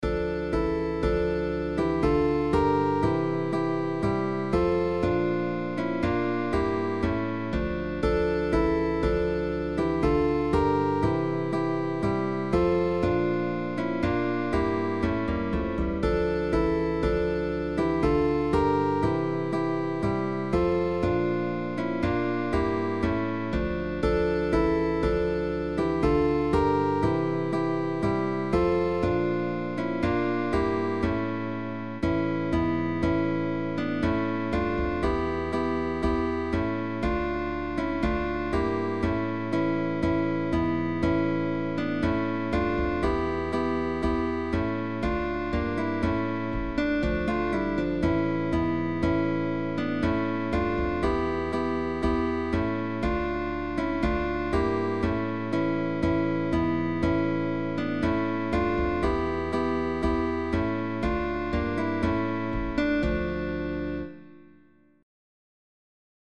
Baroque
FISCHER_Gavota_cuarteto.mp3